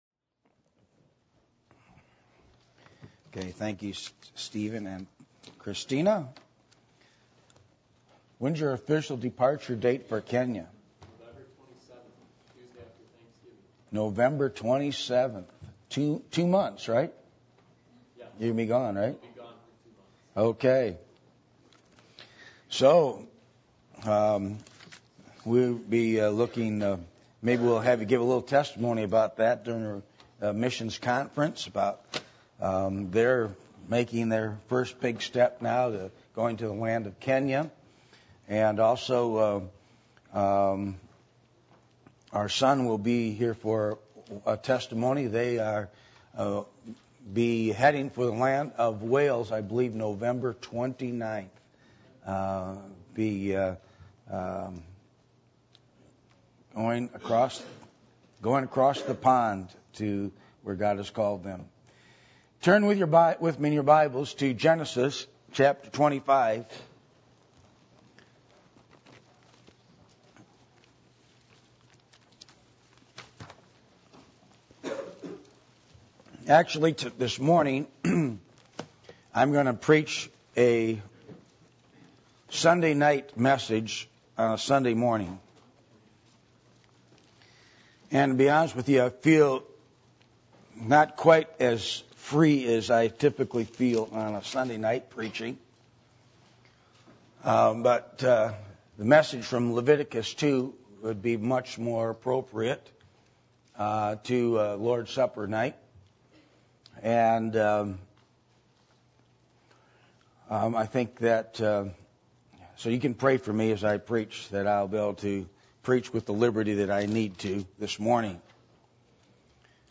Passage: Genesis 25:29-34 Service Type: Sunday Morning